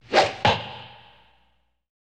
Звуки лука, стрел
Звук натянутой тетивы и стрела вонзается в цель